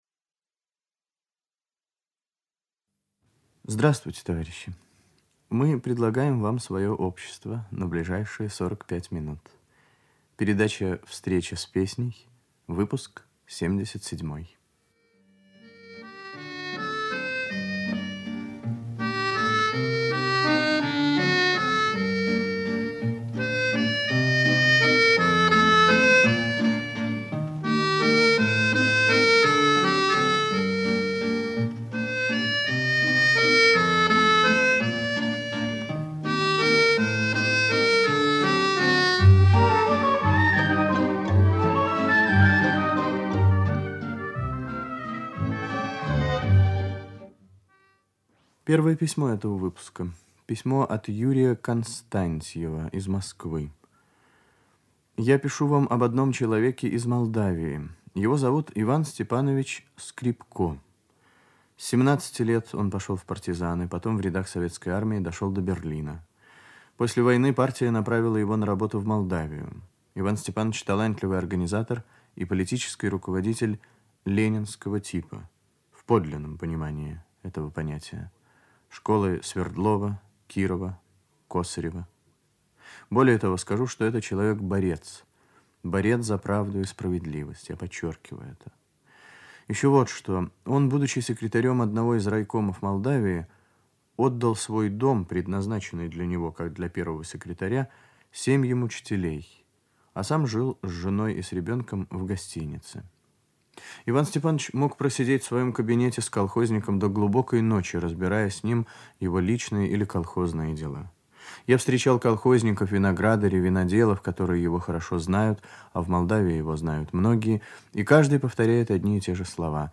Радиопередача "Встреча с песней" Выпуск 77
Ведущий - автор, Виктор Татарский